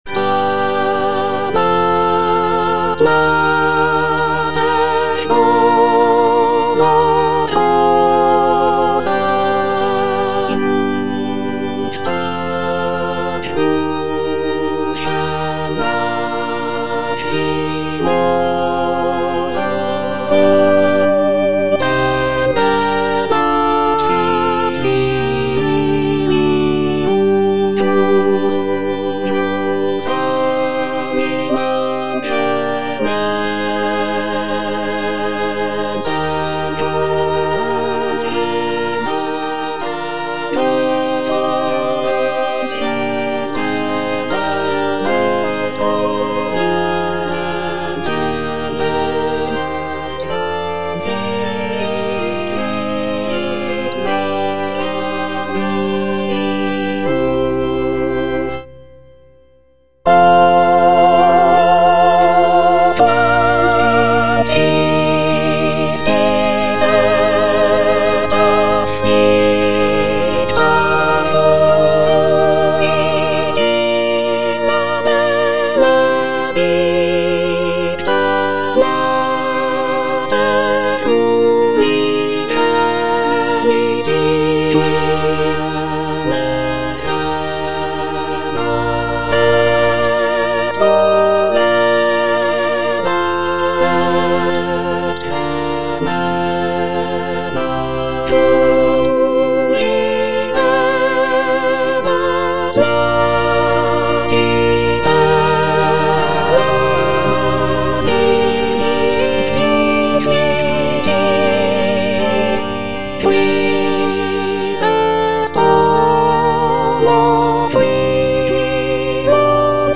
sacred choral work
Composed in the late 16th century, Palestrina's setting of the Stabat Mater is known for its emotional depth, intricate polyphonic textures, and expressive harmonies.
G.P. DA PALESTRINA - STABAT MATER Stabat Mater dolorosa (soprano I) (Emphasised voice and other voices) Ads stop: auto-stop